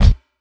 59 BD 1   -R.wav